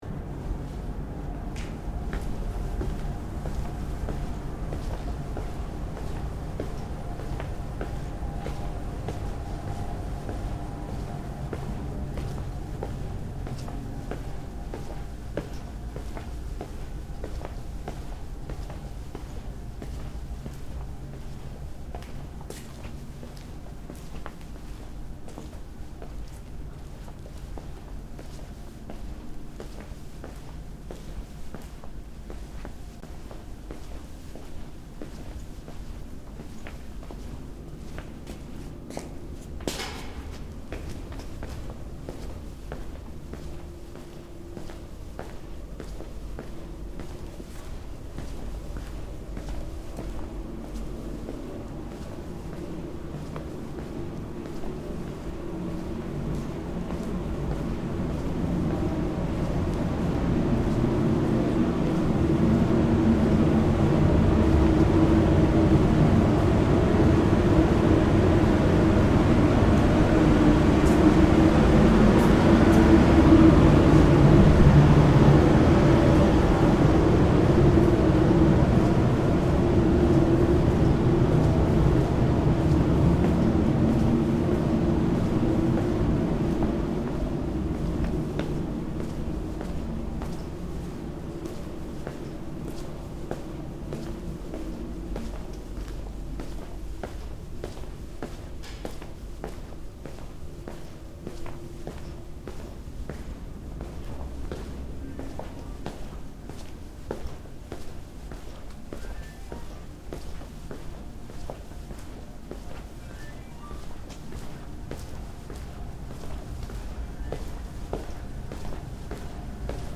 Soundscape: La Silla ESO 3.6-metre-telescope 3rd floor
Walking all the way around the third floor of the ESO 3.6-metre telescope building.
Soundscape Stereo (wav)